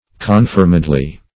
confirmedly - definition of confirmedly - synonyms, pronunciation, spelling from Free Dictionary Search Result for " confirmedly" : The Collaborative International Dictionary of English v.0.48: Confirmedly \Con*firm"ed*ly\, adv. With confirmation.